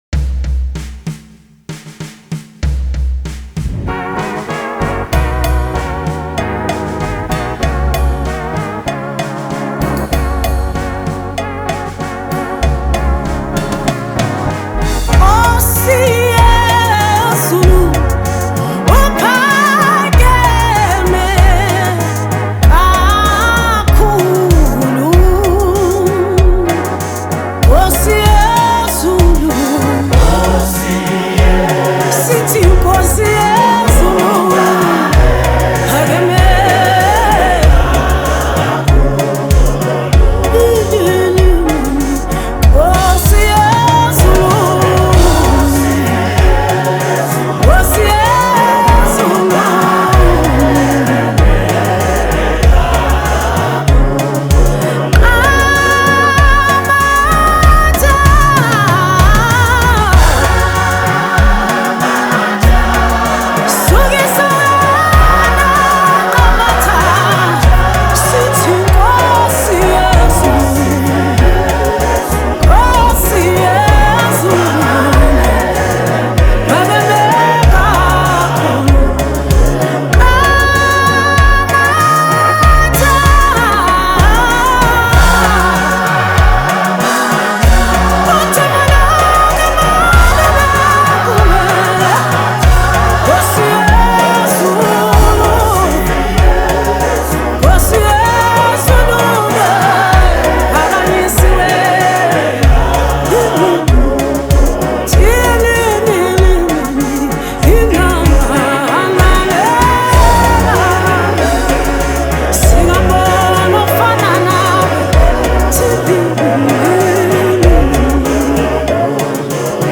Genre: Gospel/Christian.
Genre: South Africa Gospel Music